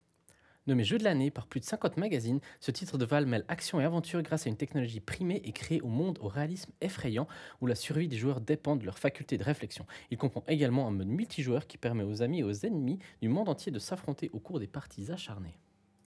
Microphone d’exception
Plutôt que des mots, des résultats; voici le rendu du microphone qui est simplement exceptionnel.